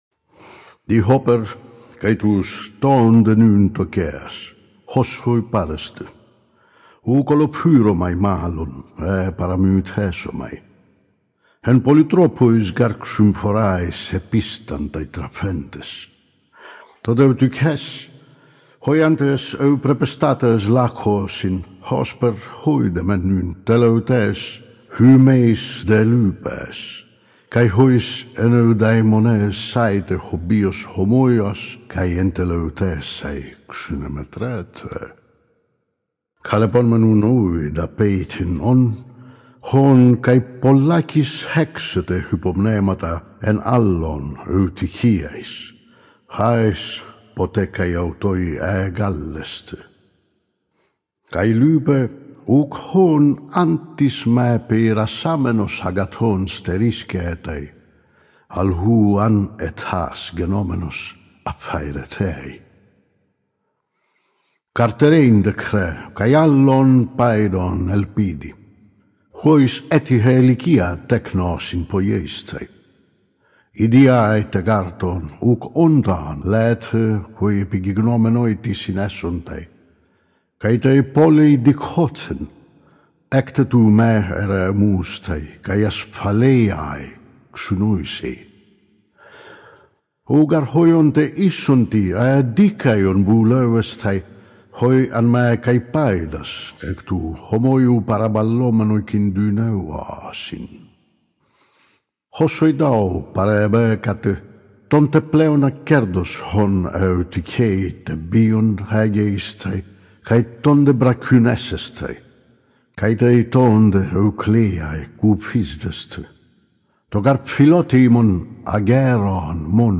Unabridged
You can listen to part B.44 of Pericles' “Funeral Oration”, an audio sample of the present audiobook.